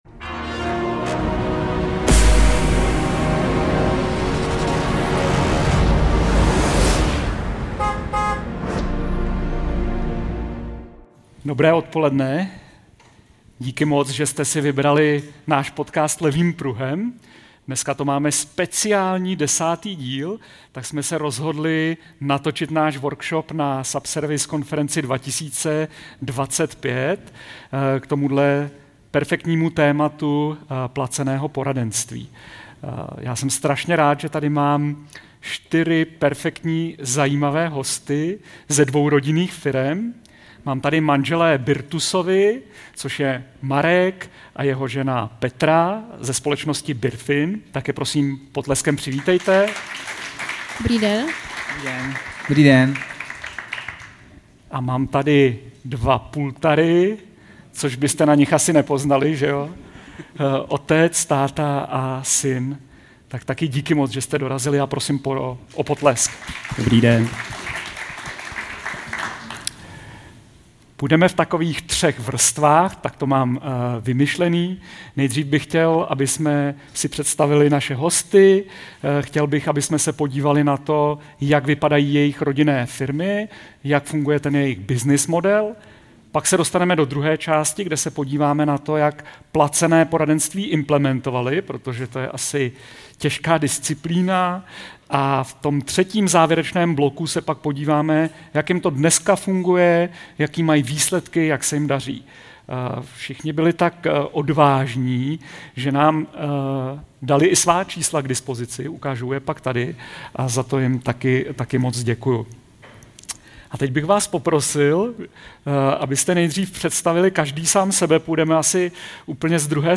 Headliner Embed Embed code See more options Share Facebook X Subscribe Konferenční speciál, ve kterém zpovídáme zástupce dvou rodinných značek.